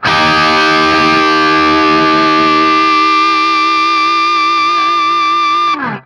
TRIAD D#  -R.wav